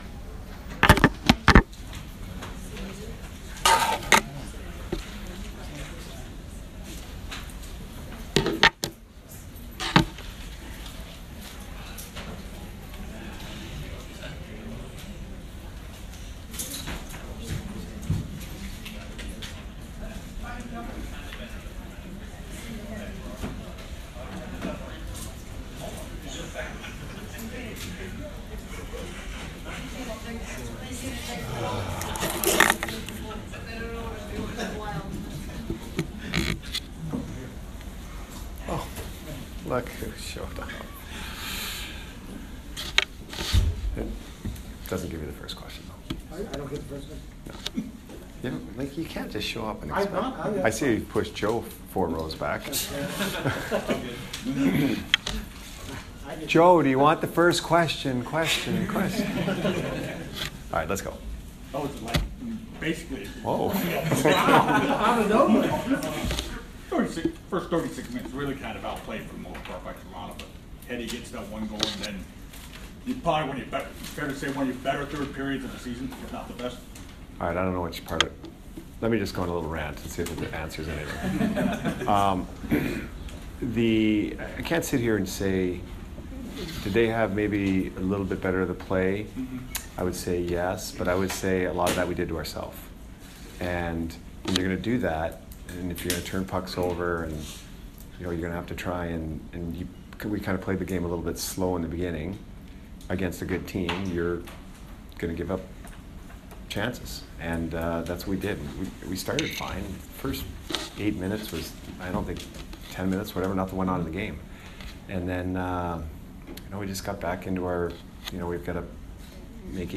Jon Cooper post-game 3/20